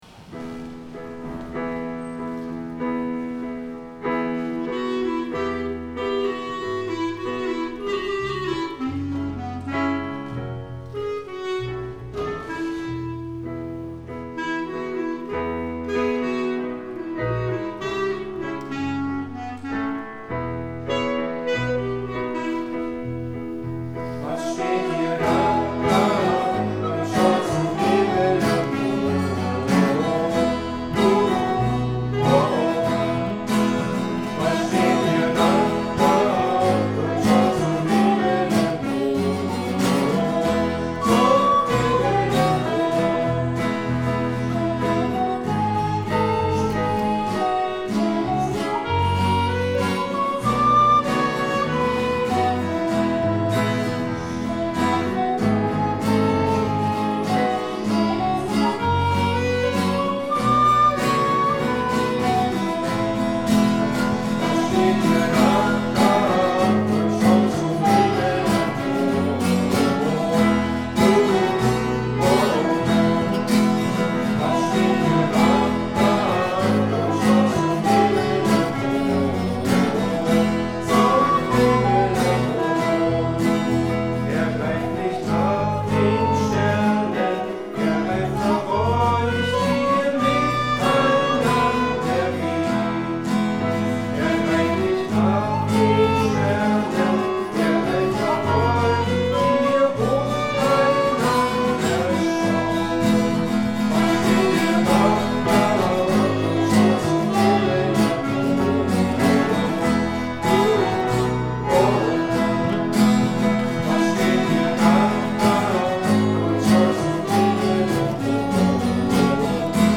Wir haben hier für euch Musik, aufgenommen im letzten Familiengottesdienst in Christus König.